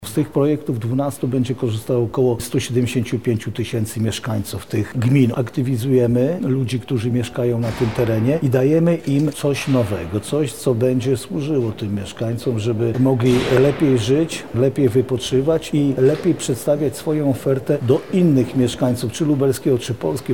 Jarosław Stawiarski – dodaje Jarosław Stawiarski.